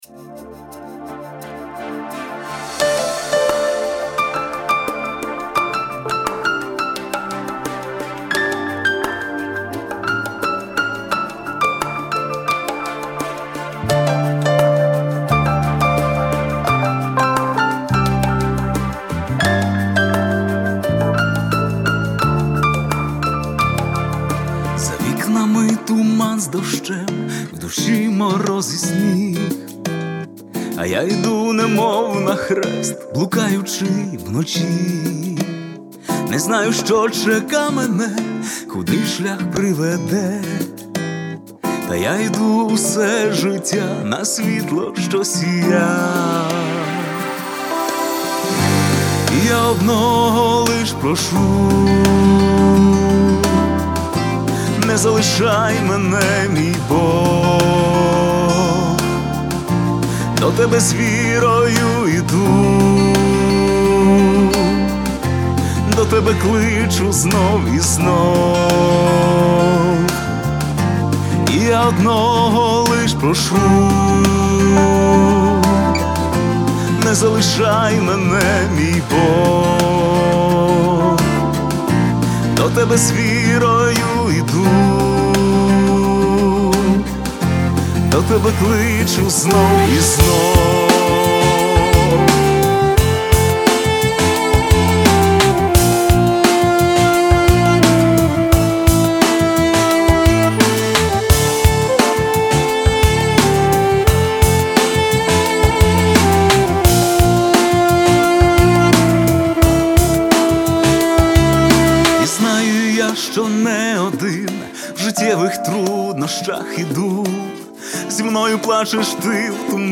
песня
1897 просмотров 398 прослушиваний 51 скачиваний BPM: 173